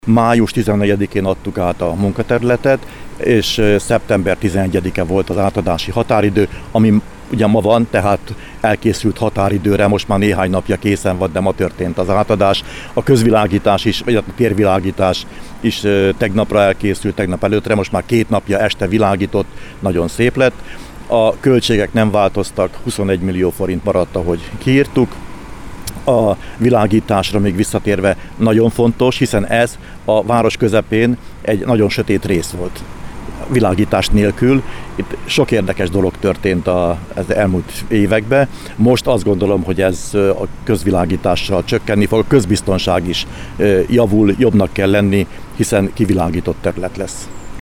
A sajtótájékoztatóval egybekötött műszaki átadáson Mezősi Árpád, a körzet önkormányzati képviselője beszélt a projekt részleteiről.